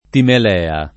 timelea [ timel $ a ]